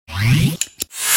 spin.mp3